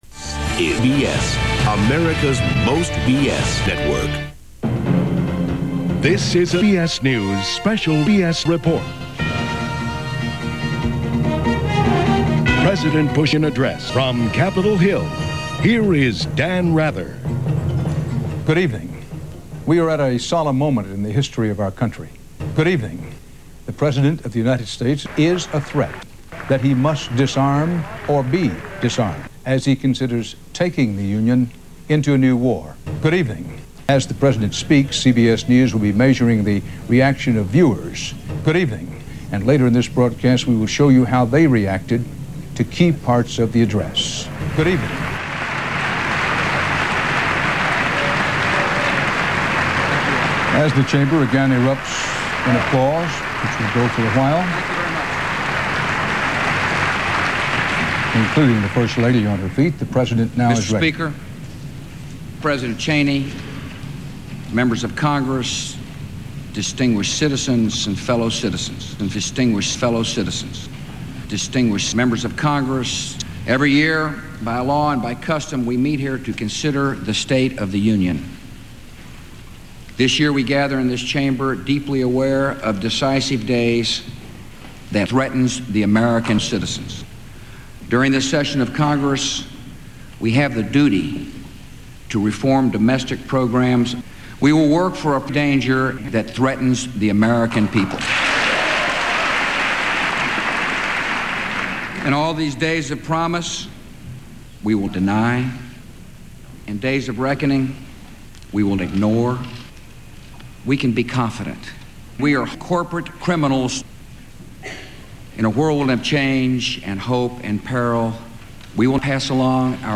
Source Material: State of the Union Address, Date Unknown